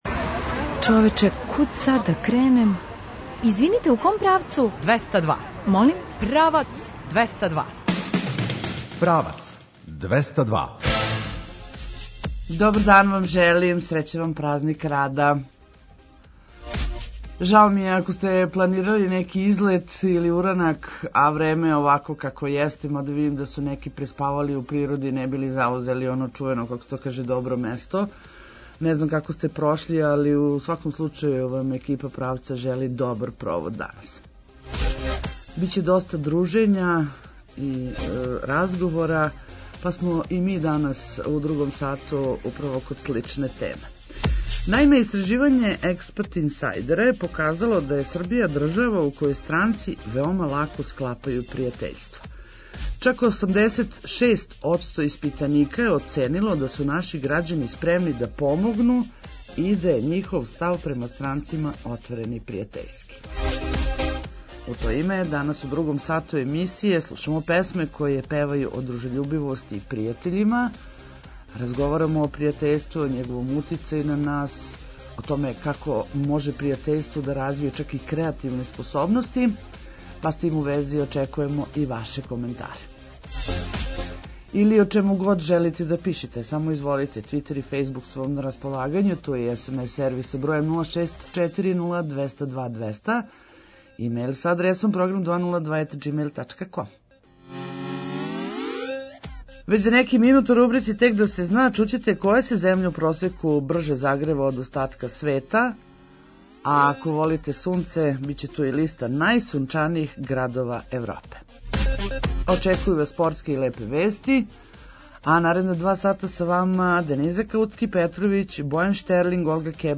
У то име, данас ћете у другом сату емисије слушати песме које певају о дружељубивости и пријатељима.